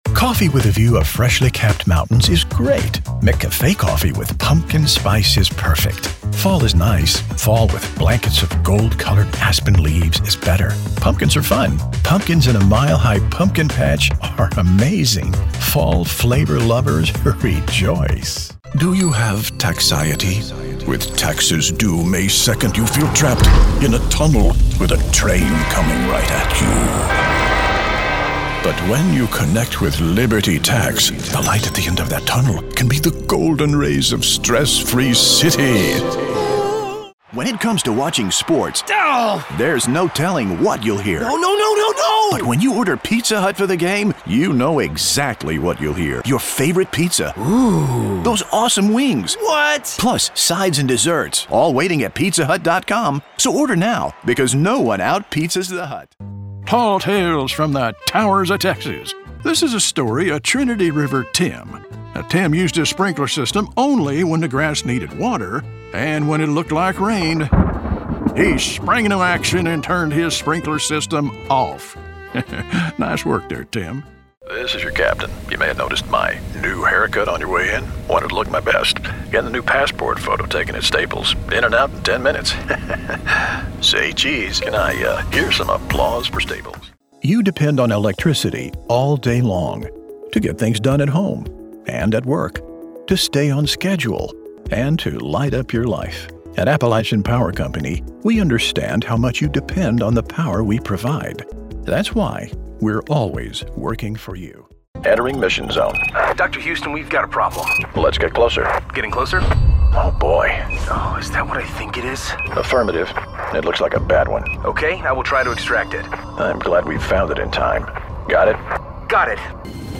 Digital Home Studio
Shure KSM 32 Large diaphragm microphone
Commercial Demo 1